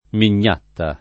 [ min’n’ # tta ]